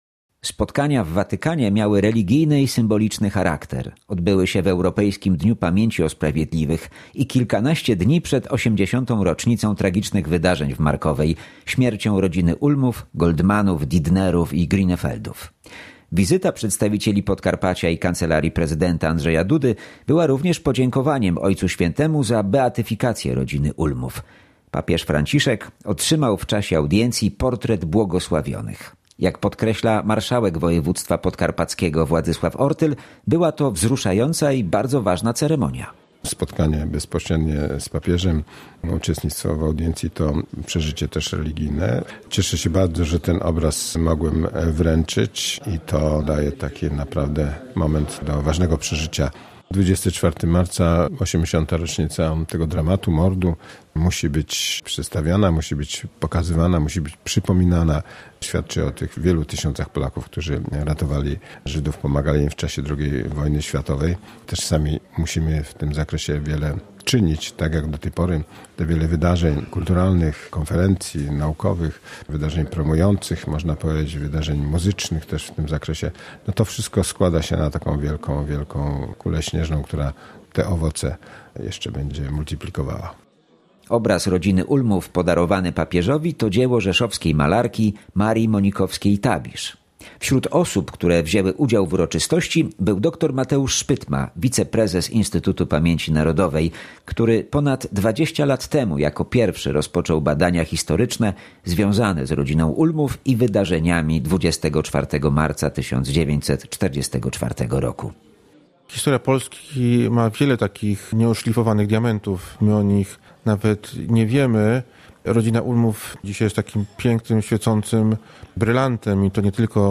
W Watykanie upamiętniono błogosławioną rodzinę Ulmów. Relacja naszego wysłannika z ceremonii w Ogrodach Watykańskich